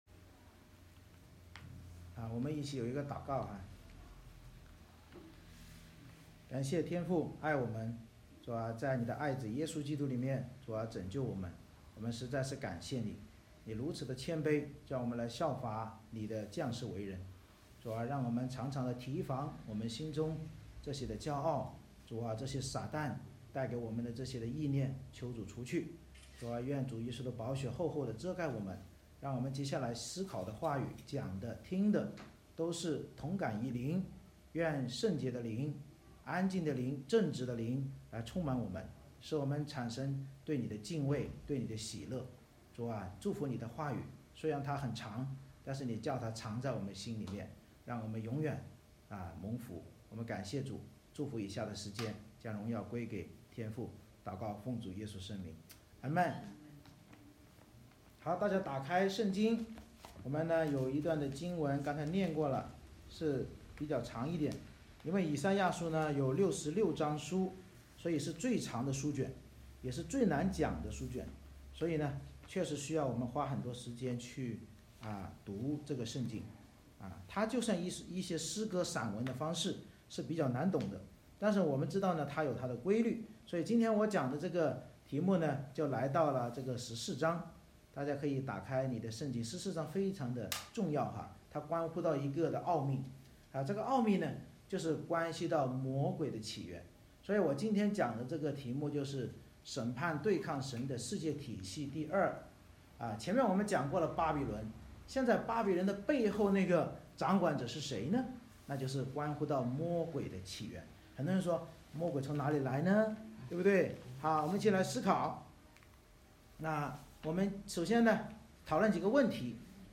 以赛亚书14:1-27 Service Type: 主日崇拜 先知作诗预言那对抗神的以巴比伦为首的世界体系之结局，揭露世界体系背后灵界掌权者堕落的起源，警告我们骄傲必敌对主耶稣基督并堕入地狱。